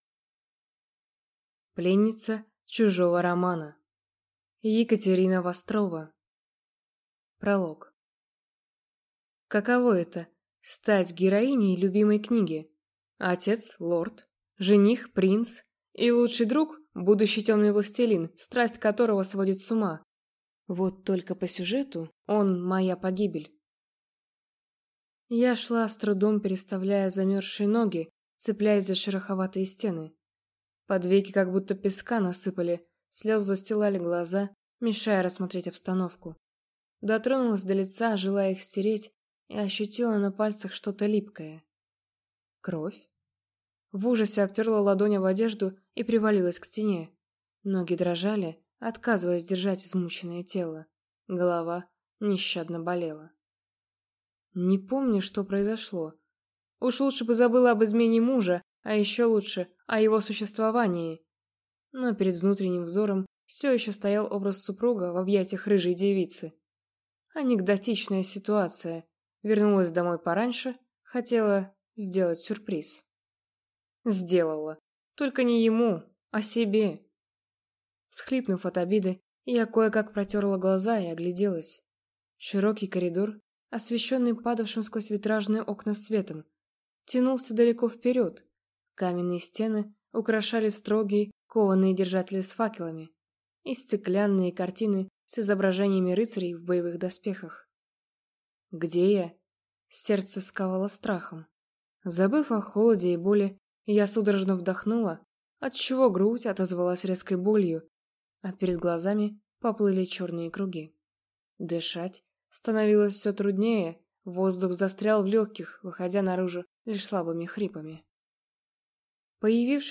Аудиокнига Пленница чужого романа | Библиотека аудиокниг